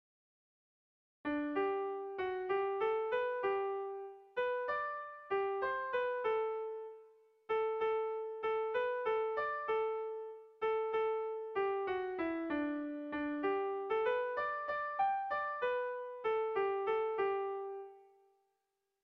Erromantzea
ABD..